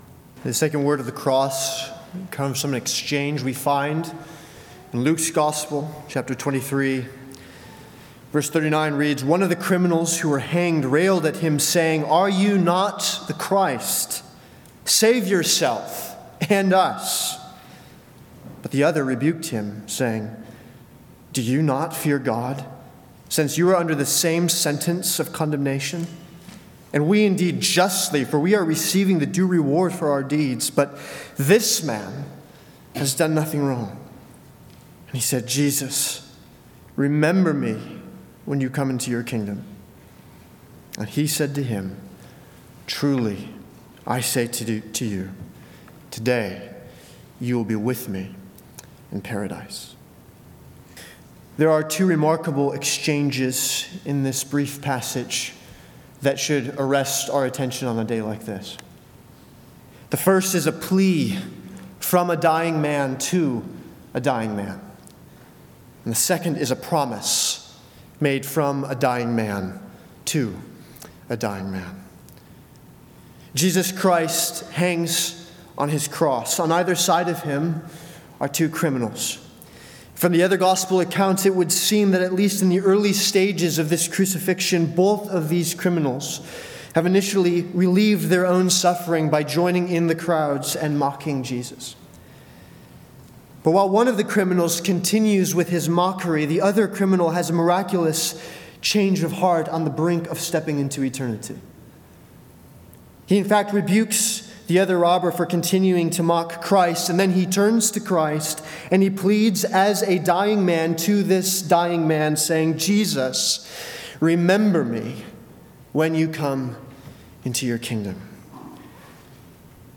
Community Good Friday 2025 2nd Word